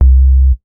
MoogSub 004.WAV